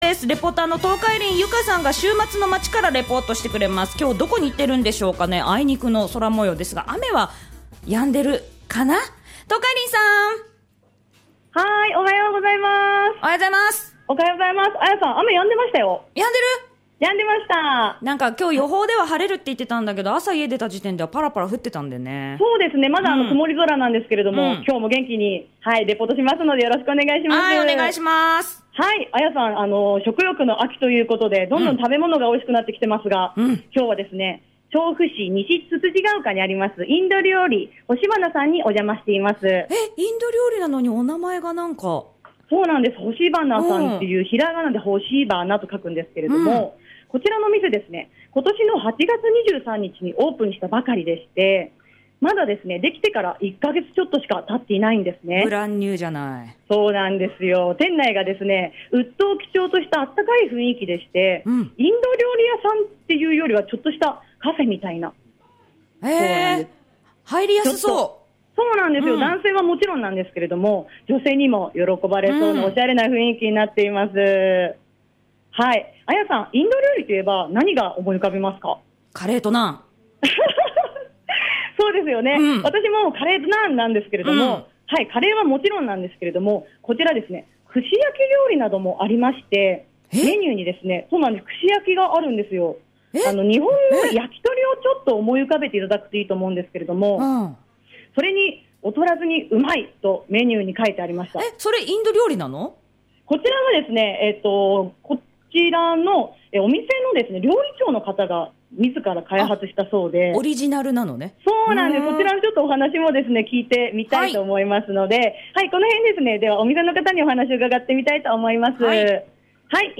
インド料理 ほしばな さんにレポート行ってきましたよ♬